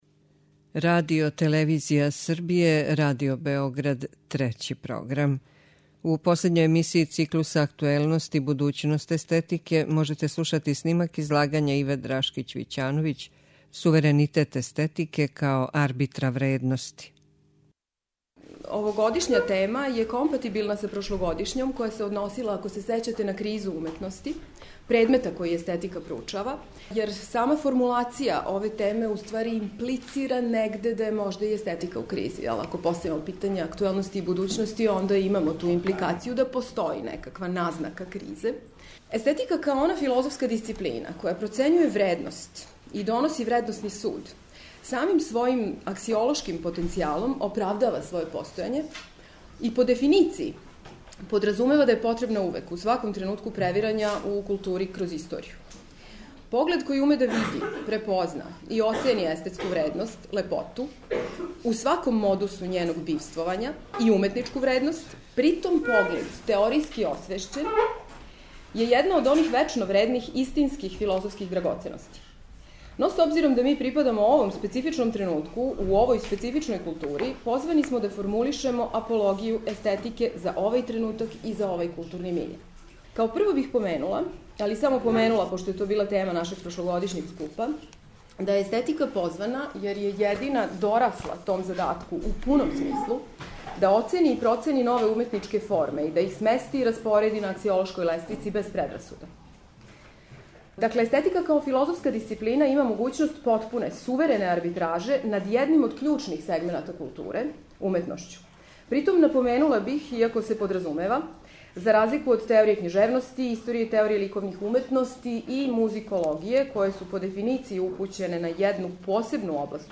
Научни скупови
преузми : 7.99 MB Трибине и Научни скупови Autor: Редакција Преносимо излагања са научних конференција и трибина.